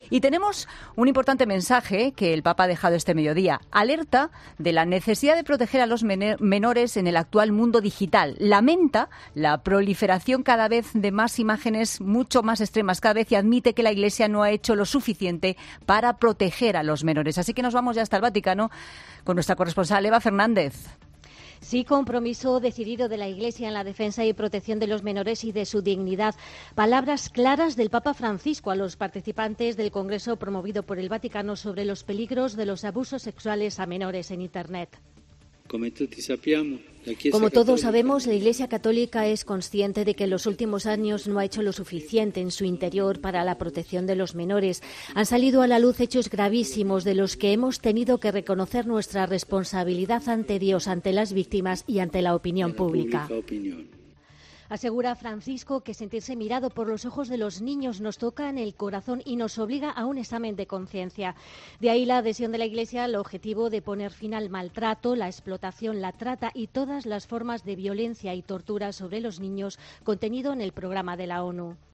El Papa Francisco ha sido claro en su discurso sobre la defensa y protección de los menores y de su dignidad, ante los participantes del congreso promovido por el Vaticano. El Santo Padre ha hecho referencia a los peligros de los abusos sexuales a los menores en Internet.